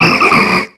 Cri d'Okéoké dans Pokémon X et Y.